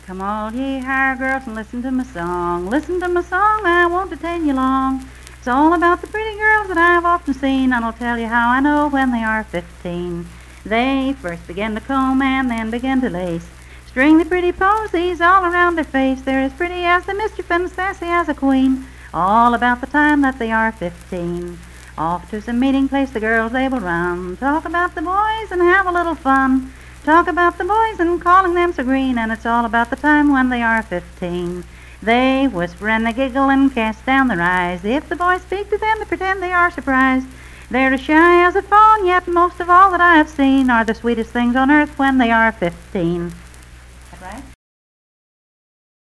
Unaccompanied vocal music
Verse-refrain 4(4). Performed in Coalfax, Marion County, WV.
Voice (sung)